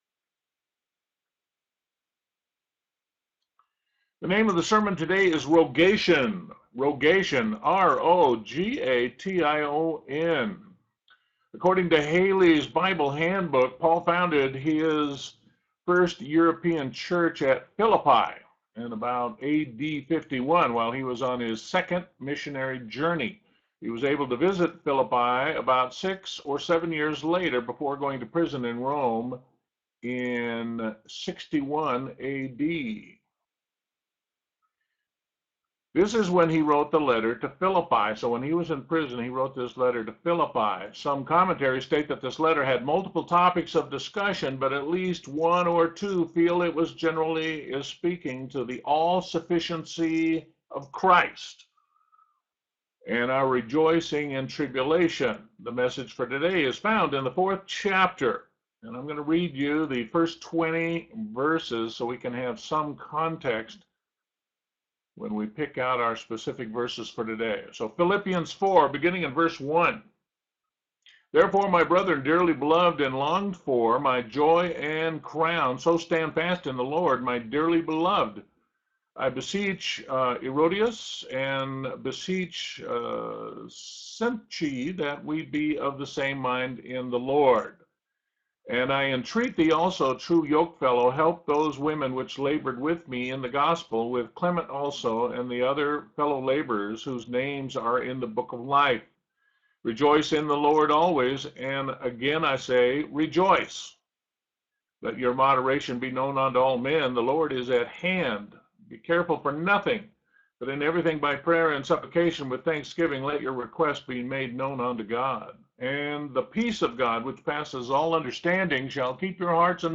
Sermon: Rogation